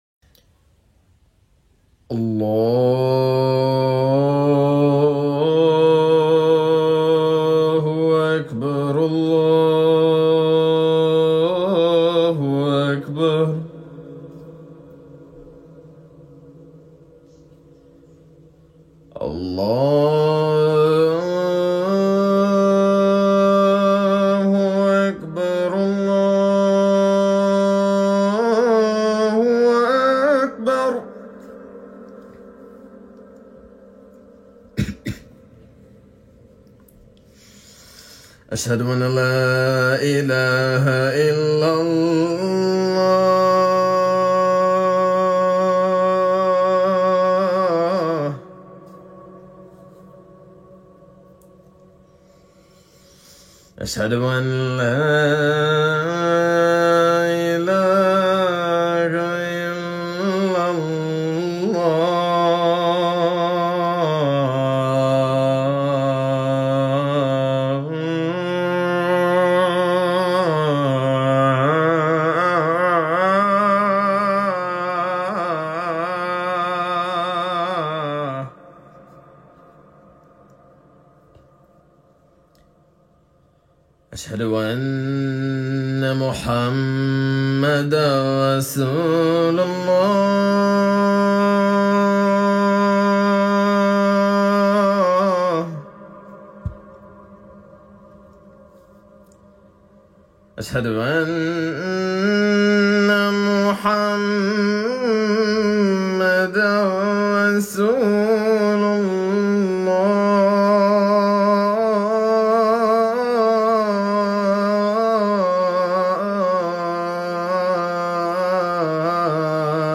Adhan, 즉 기도 요청을 하는 남성